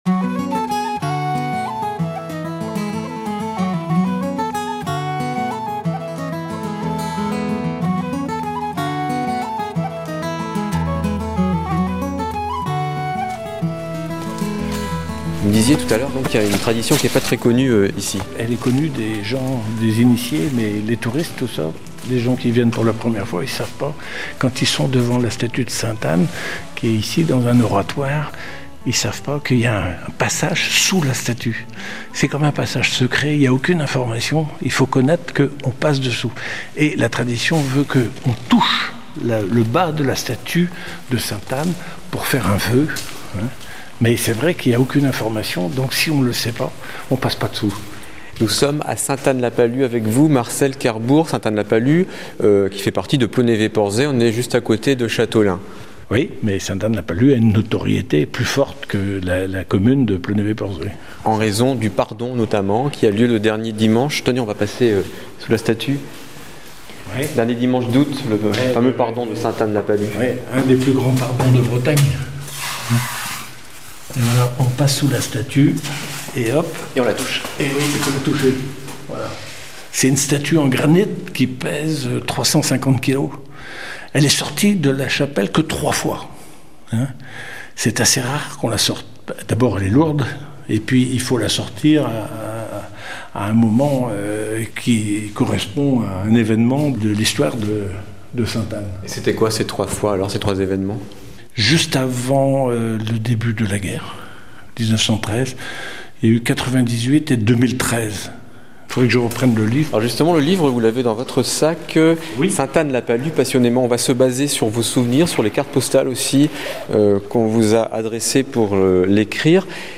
Interview de l’auteur
interview-rcf.mp3